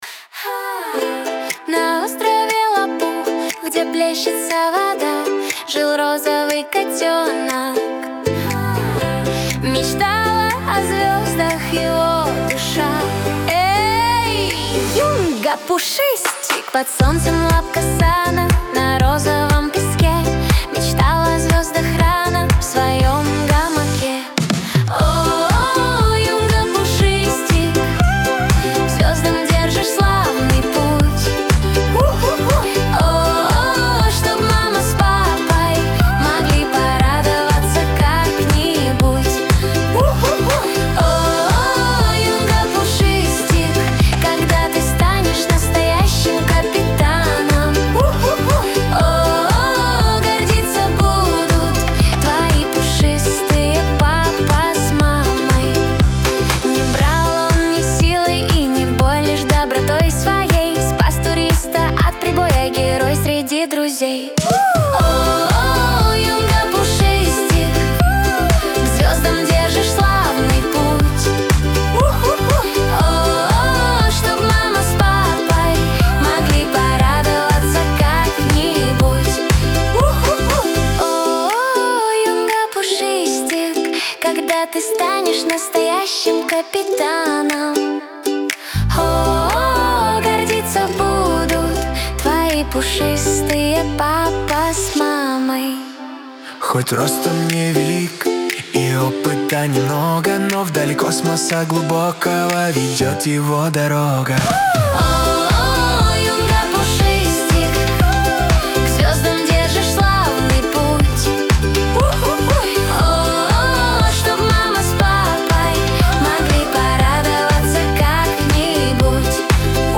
версия фермерских дискотек). Саундтрек